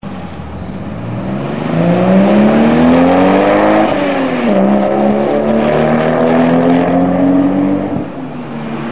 Car Sounds
Sound_Effects___Car_Sounds___Toyota_Supra_Turbo__Engine_Rev.wav